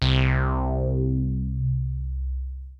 MOOGY SYNTH 1.wav